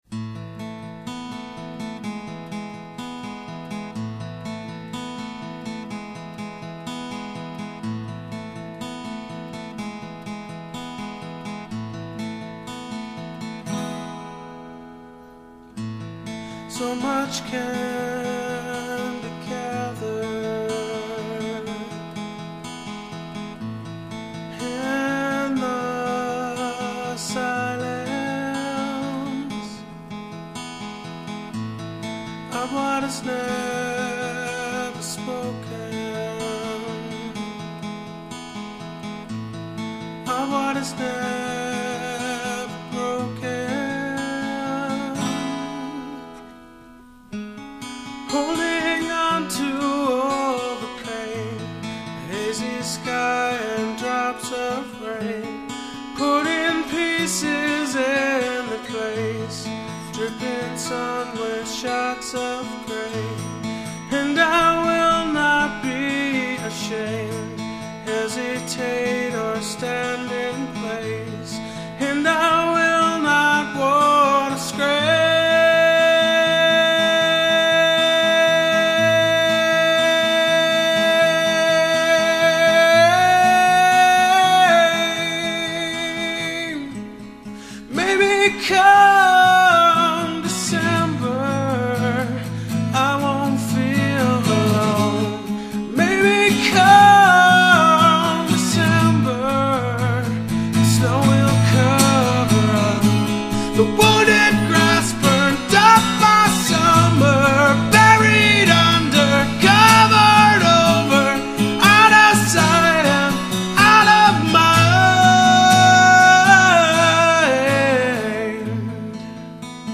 This recording was done live
acoustic